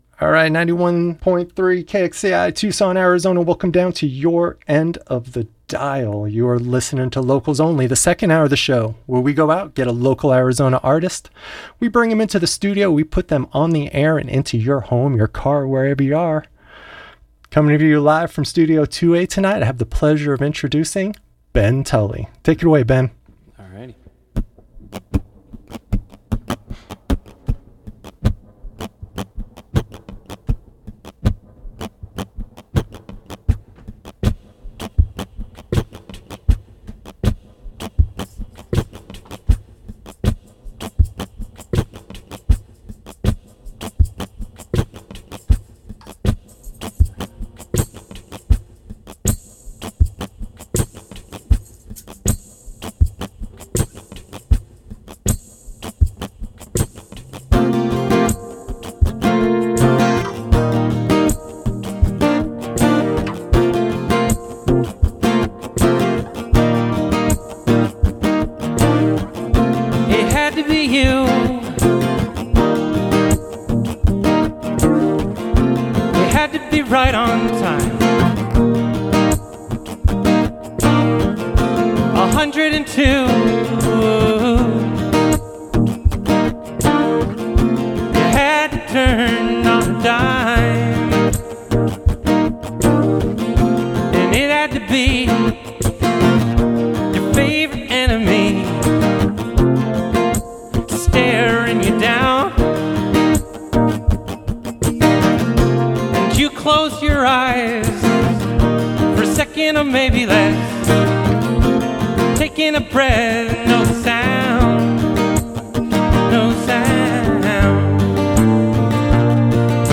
live in Studio 2A
Listen to the live performance + interview here!
Singer/Songwriter